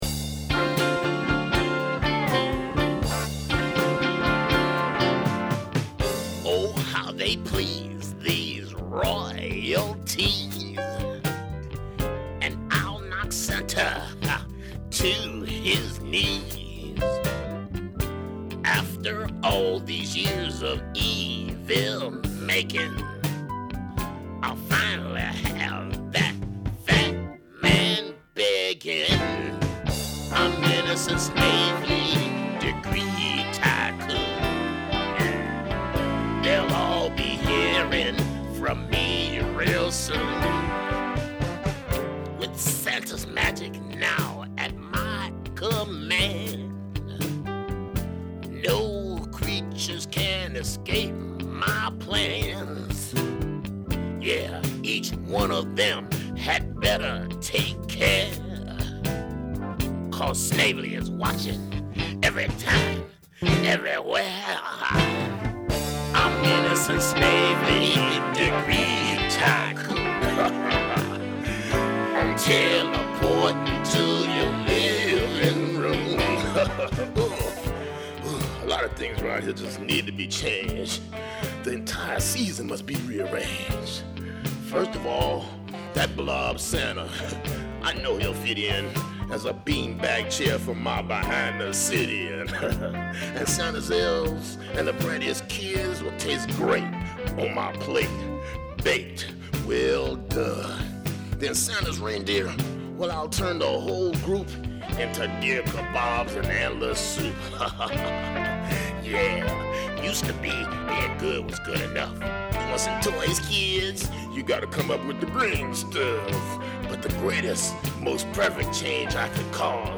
The comedy musical features seven songs.